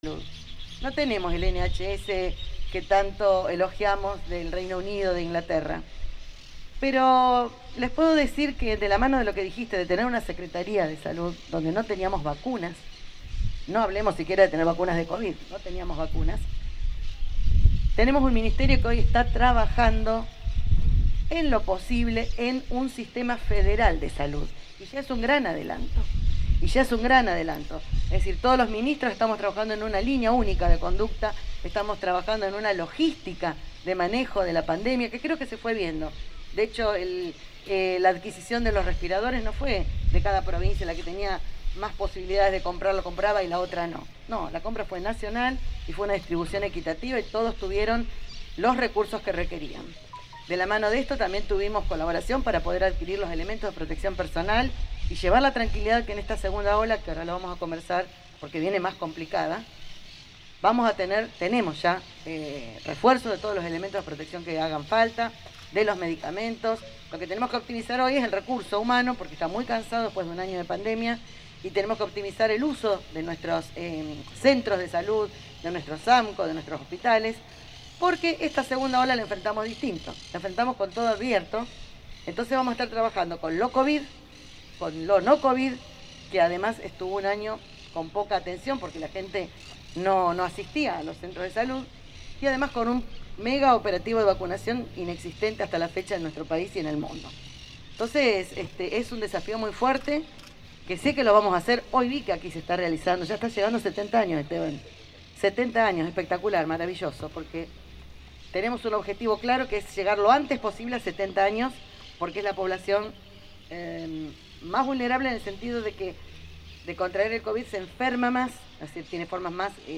Compartimos palabras de la Ministra de Salud, Sonia Martorano